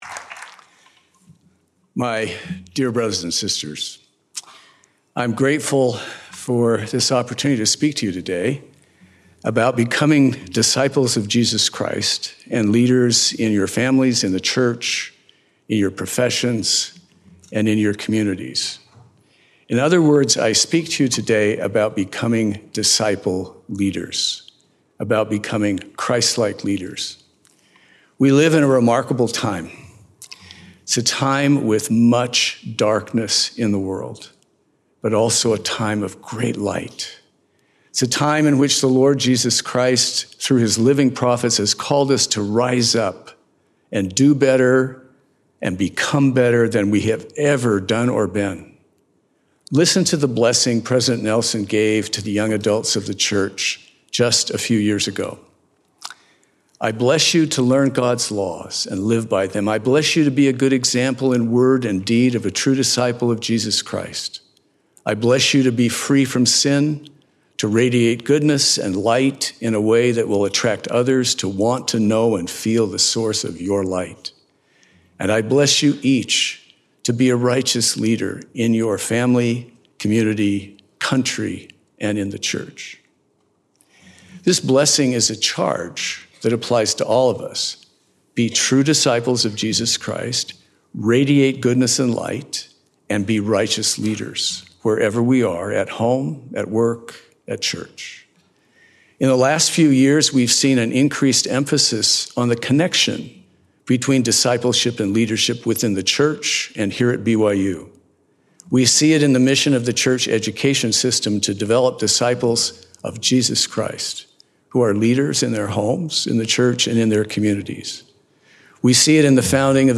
Becoming a Disciple-Leader | Kim B. Clark | BYU Speeches
Audio recording of Becoming a Disciple-Leader by Kim B. Clark